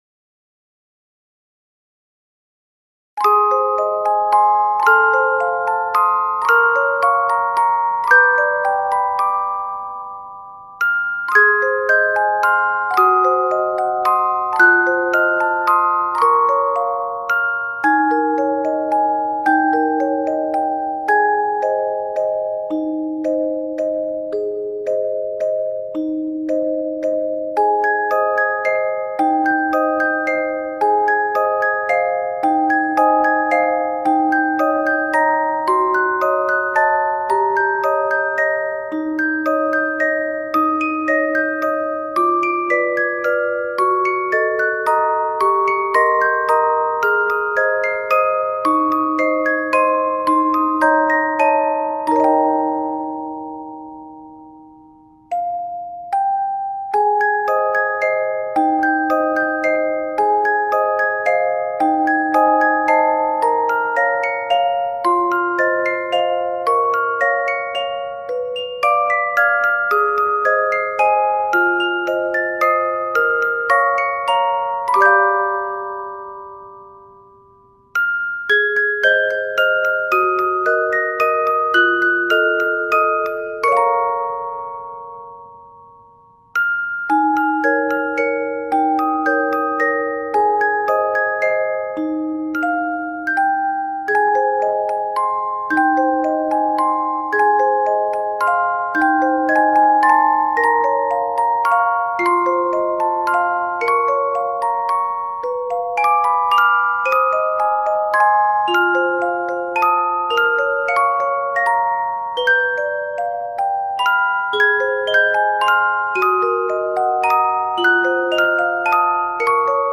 Music Box Version